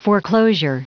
Prononciation du mot foreclosure en anglais (fichier audio)
Prononciation du mot : foreclosure